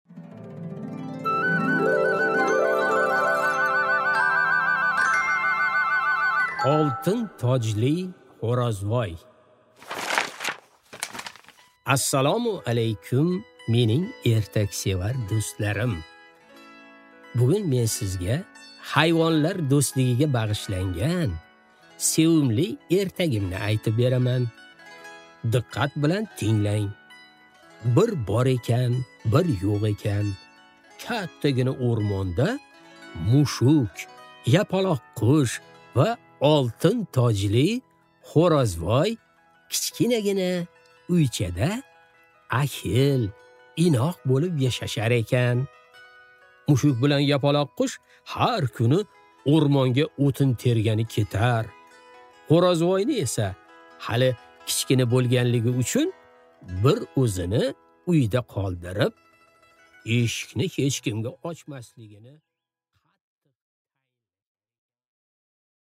Аудиокнига Oltin tojli xo'rozvoy